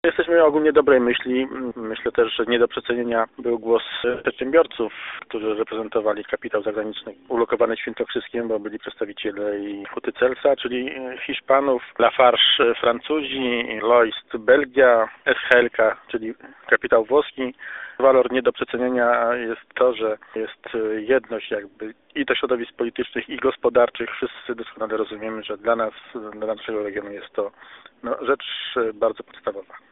Pliki do pobrania Wypowiedź wojewody Grzegorza Banasia (Polskie Radio Kielce) 1.14 MB Data publikacji : 02.07.2013 16:53 Drukuj Generuj PDF Powiadom Powrót